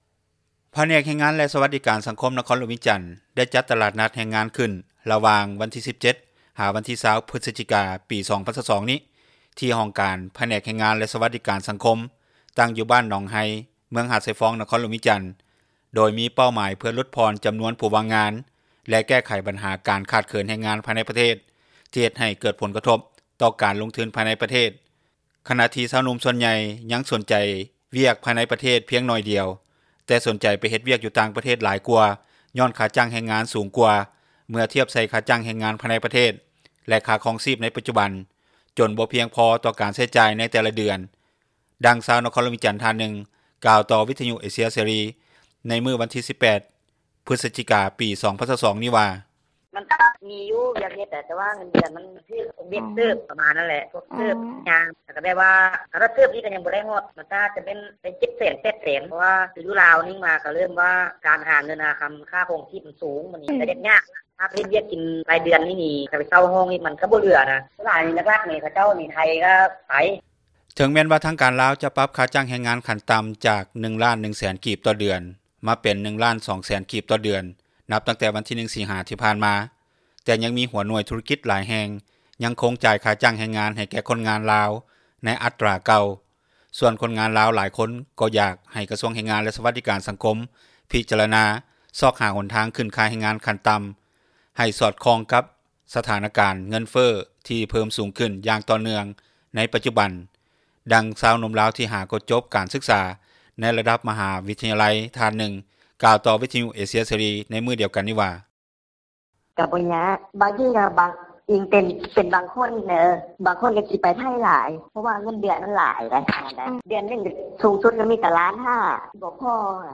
ດັ່ງຊາວ ນະຄອນຫລວງວຽງຈັນ ທ່ານນຶ່ງກ່າວຕໍ່ວິທຍຸ ເອເຊັຽເສຣີ ໃນມື້ວັນທີ 18 ພຶສຈິກາ 2022 ນີ້ວ່າ:
ດັ່ງຊາວໜຸ່ມລາວ ທີ່ຫາກໍຈົບການສຶກສາ ໃນລະດັບມຫາວິທຍາໄລ ທ່ານນຶ່ງກ່າວຕໍ່ວິທຍຸ ເອເຊັຽເສຣີ ໃນມື້ດຽວກັນນີ້ວ່າ:
ດັ່ງ ຊາວນະຄອນຫລວງວຽງຈັນ ອີກທ່ານນຶ່ງກ່າວວ່າ:
ດັ່ງພນັກງານ ຝ່າຍບຸກຄົນຂອງບໍຣິສັດ ແຫ່ງນຶ່ງ ຢູ່ນະຄອນຫລວງວຽງຈັນ ກ່າວວ່າ: